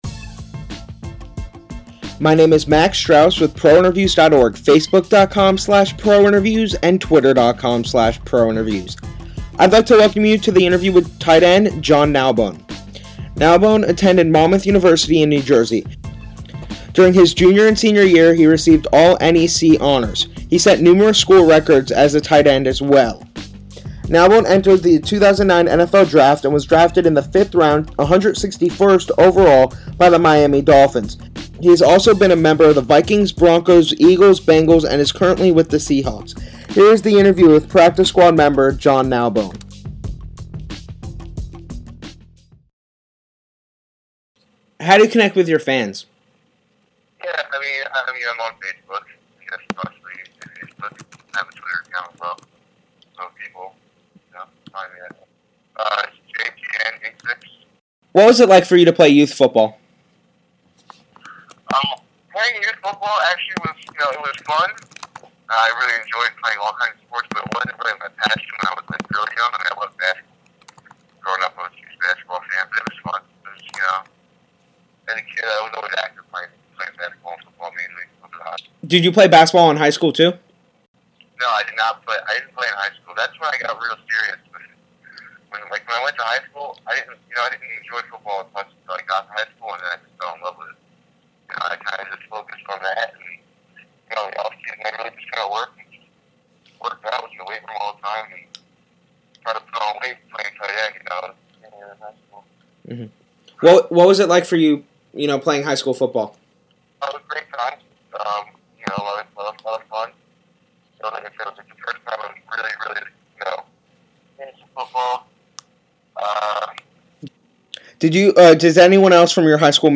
This interview was done right after the 2011 season.